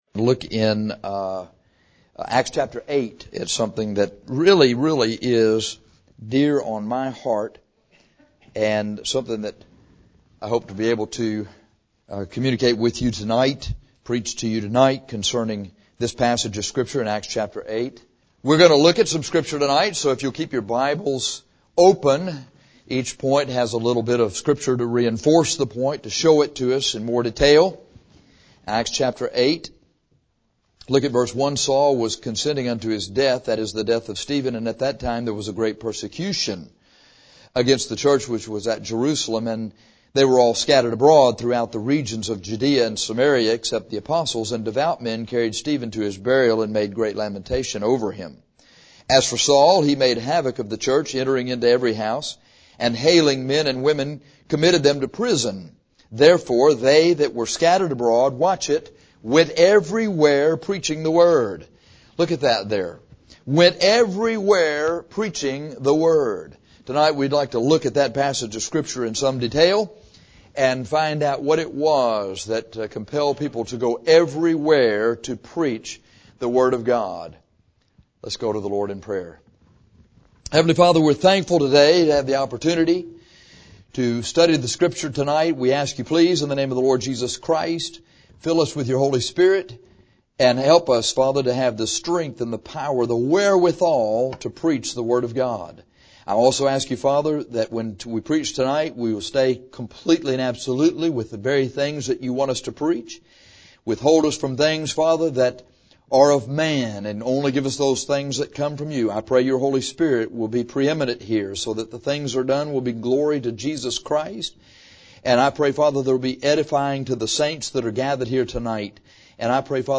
This sermon reveals how they did it and what we must do to faithfully reach the lost the way they did.